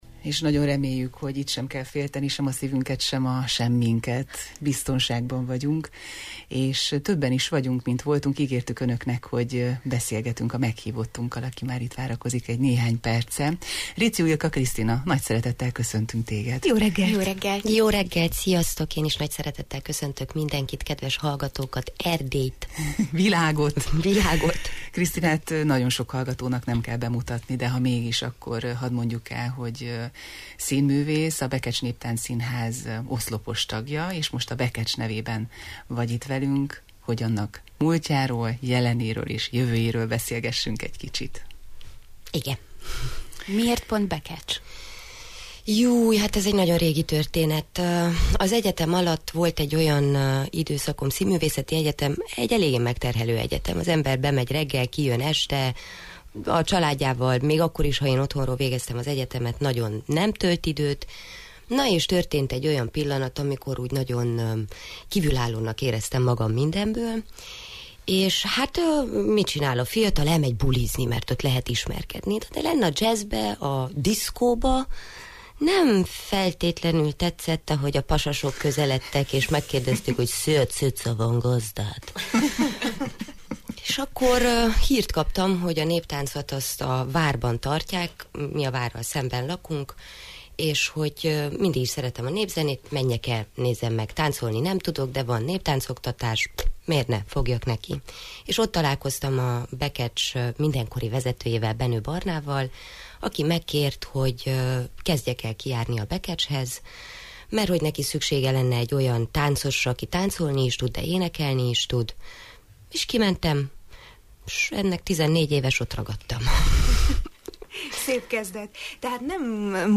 Mi az, ami az autentikus folklór és tánc világából újra és újra bevonzza az alkotót és nézőt egyaránt? Megtudják beszélgetésünkből: